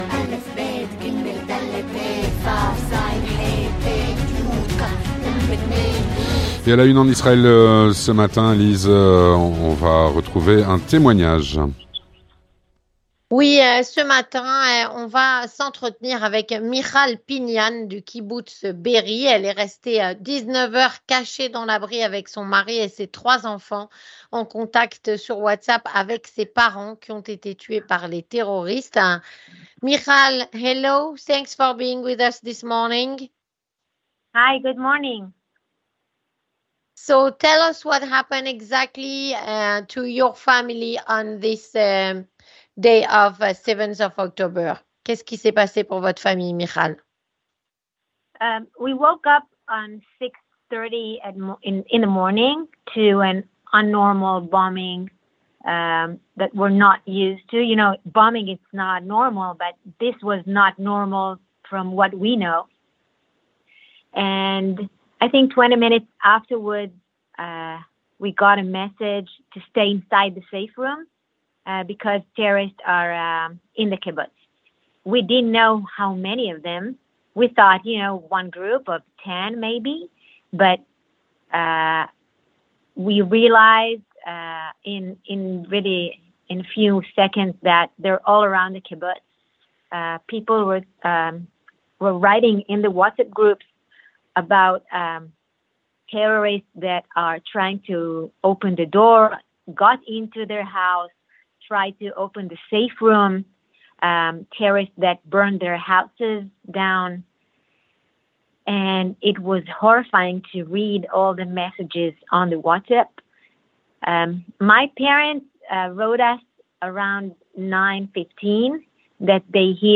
À la Une en Israël - Témoignage